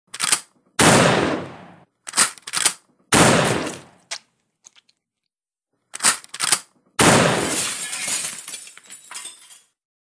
Shotgun